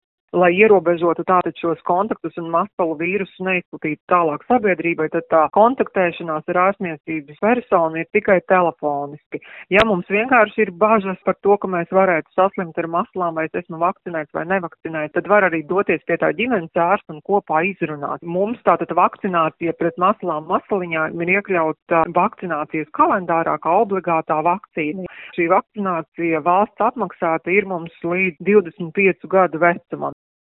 intervijā